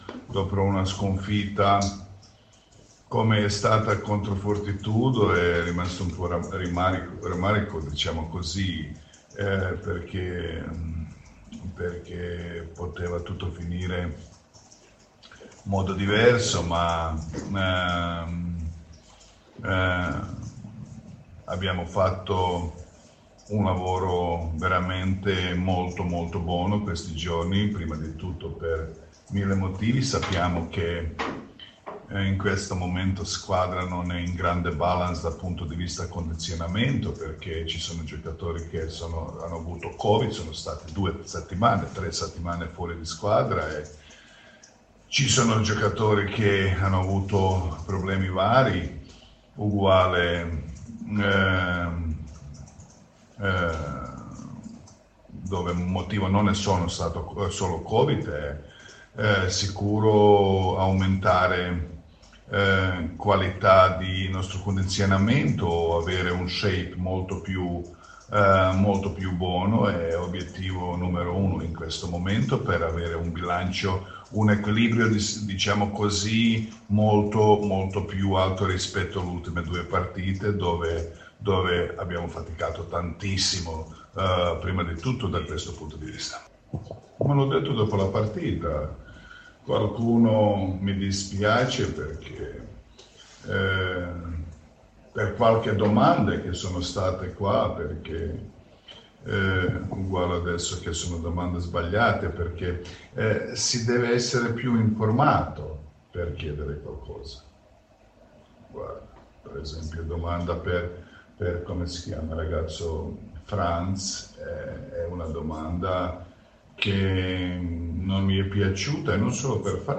La 12^ giornata di Serie A, vedrà i biancorossi impegnati sul parquet del PalaPentassuglia per la sfida contro la capolista Happy Casa Brindisi, con palla a due alle ore 20, in programma domani, Sabato 19 Dicembre. Coach Jasmin Repesa analizza la sfida ai nostri microfoni.